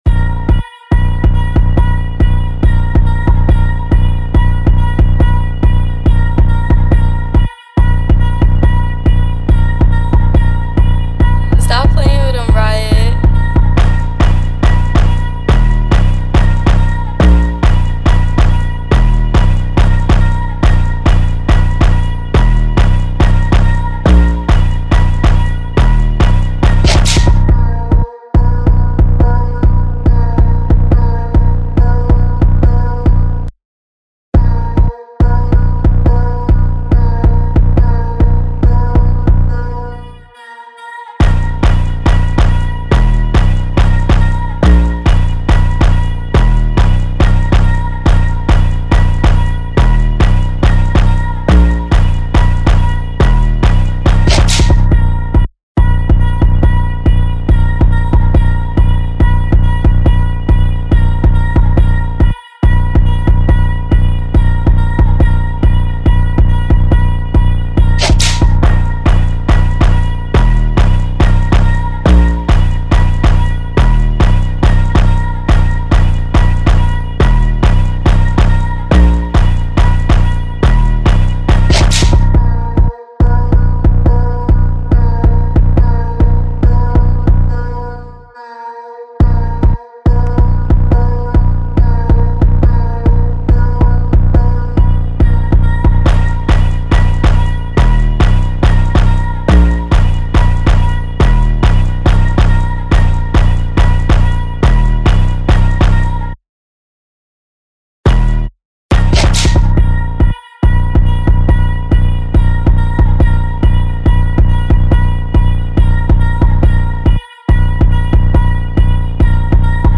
2023 in Jersey Drill Instrumentals